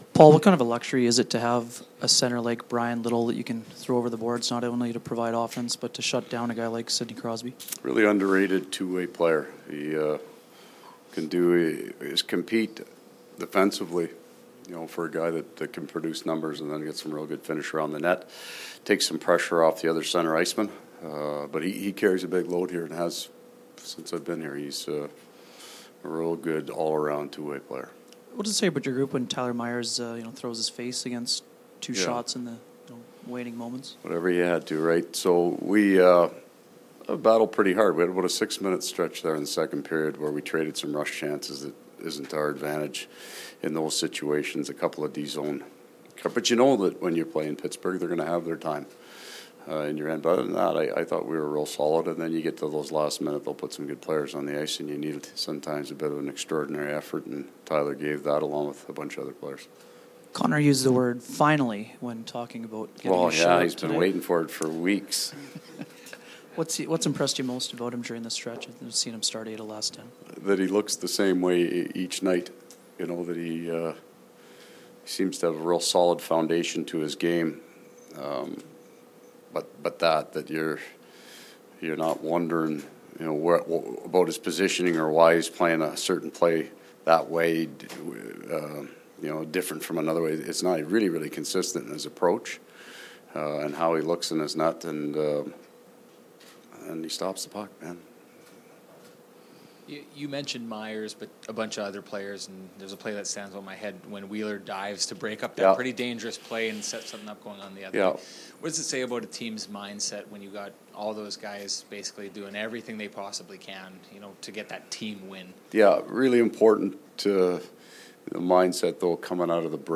Winnipeg Jets coach Paul Maurice post-game press conference.